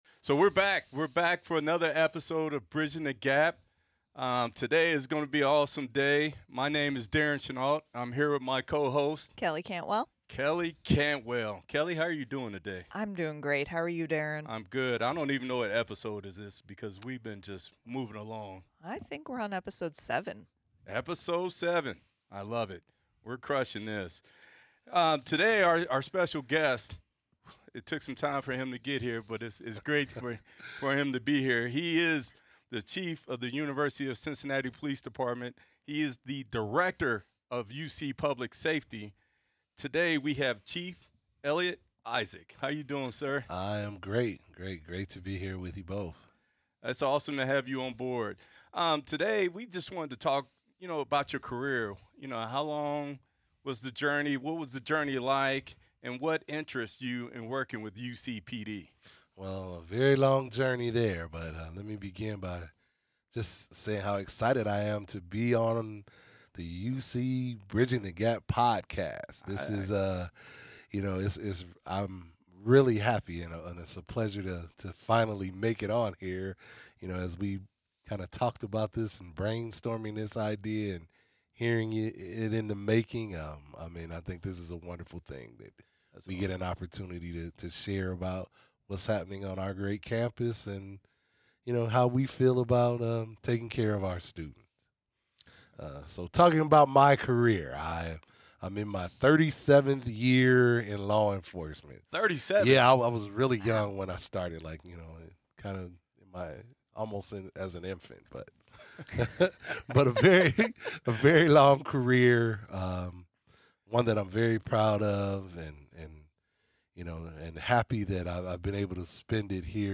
Keeping Bearcats Safe: A Conversation with UC Police Chief Eliot Isaac